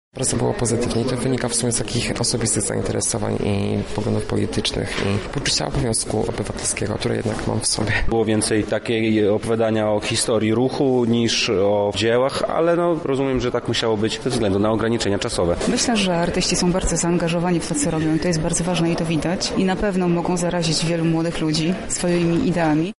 prezentacja faszyzm
Spotkanie miało miejsce w Galerii Labirynt.